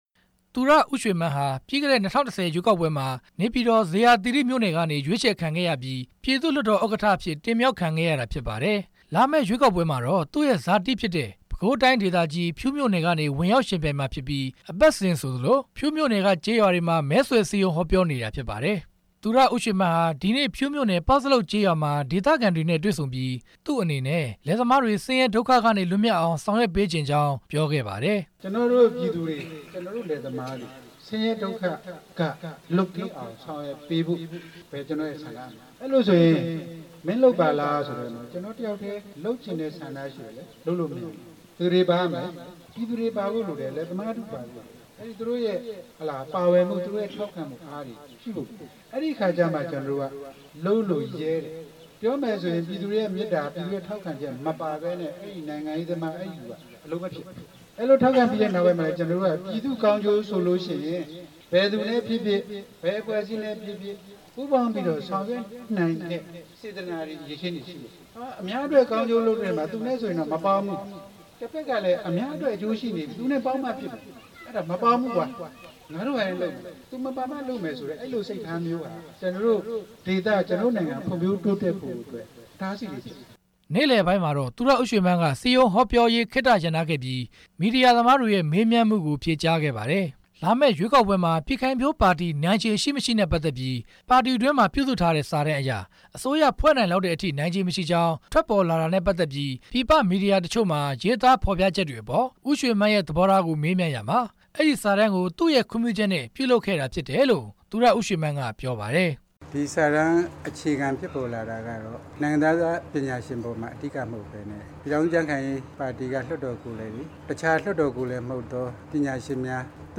ဒီနေ့ ပဲခူးတိုင်းဒေသကြီး ဖြူးမြို့နယ် ပေါက်ဇလုပ်ကျေးရွာမှာ စည်းရုံးဟောပြောအပြီး သတင်းထောက်တွေ က မေးမြန်းရာမှာ သူရဦးရွှေမန်းက ဖြေကြားခဲ့တာဖြစ်ပါတယ်။